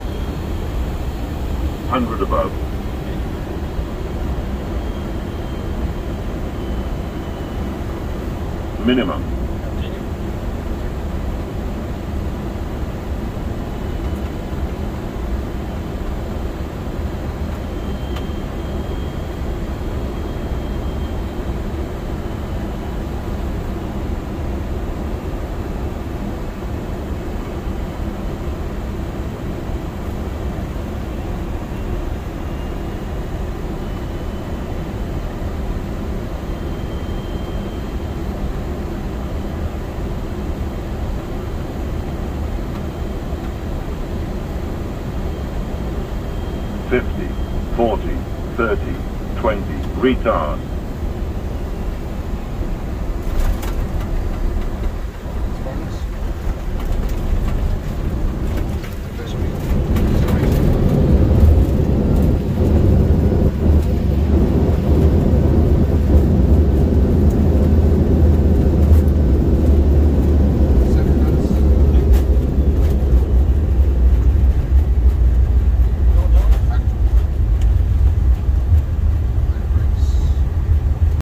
AirGreenland A330 cockpit landing at sound effects free download
AirGreenland A330 cockpit landing at Kangerlussuaq, Greenland